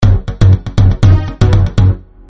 ufo_17165.mp3